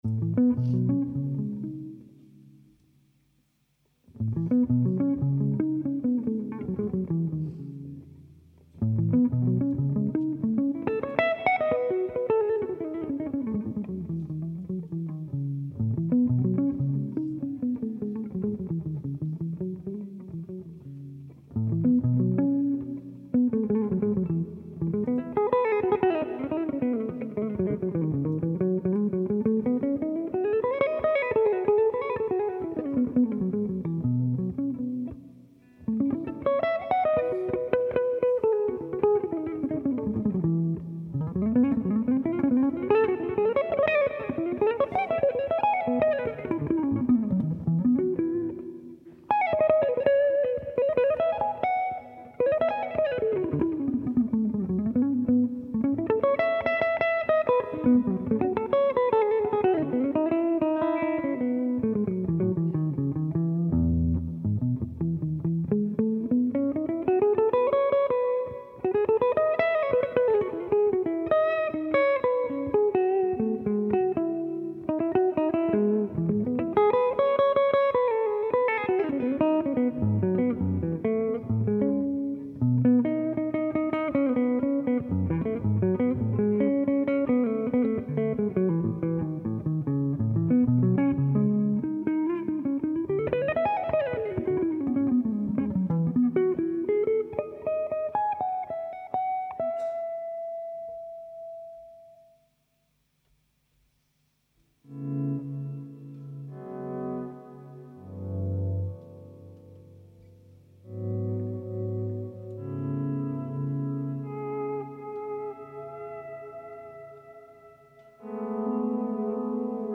guitar
alto saxophone
drums